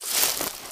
HauntedBloodlines/STEPS Bush, Walk 20.wav at main
STEPS Bush, Walk 20.wav